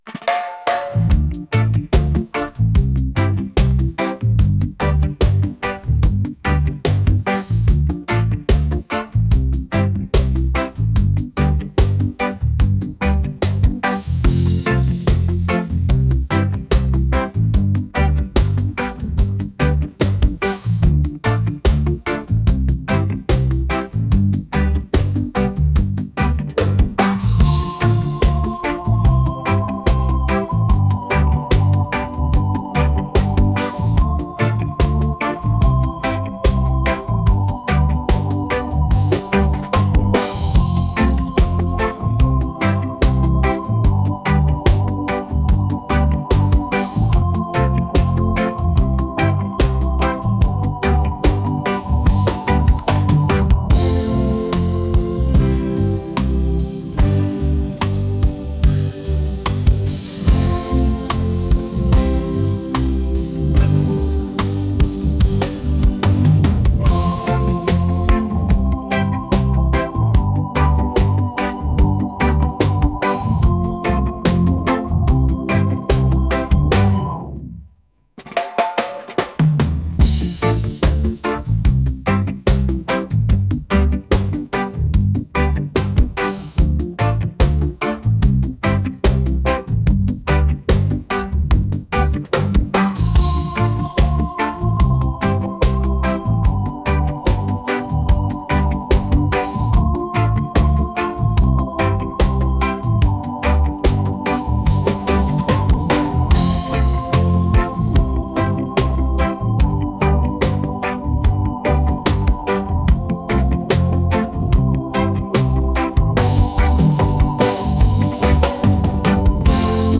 reggae | lounge | acoustic